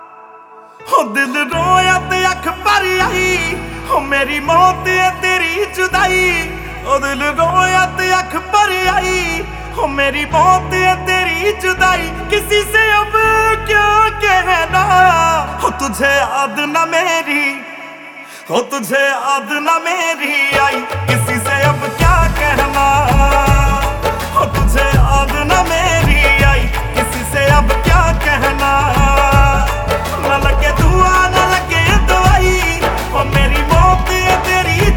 # Bollywood